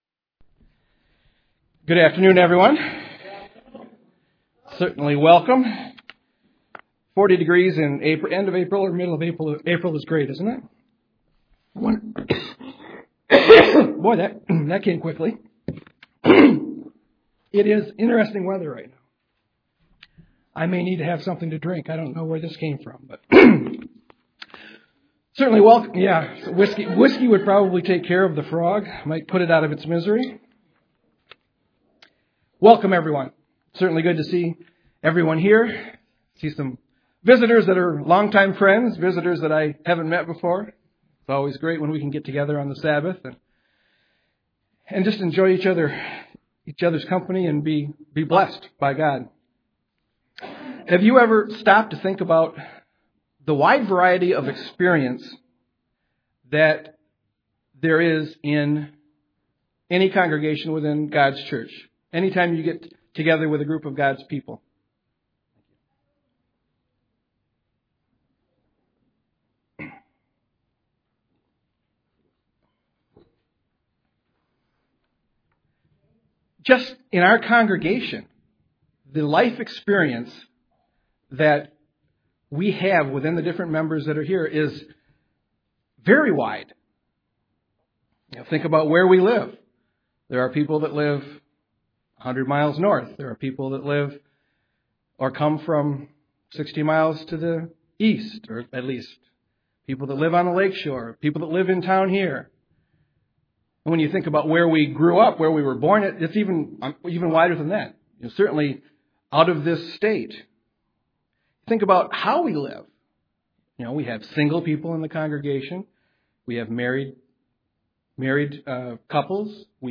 Listen to learn some lessons on how to use our differences to work together UCG Sermon Studying the bible?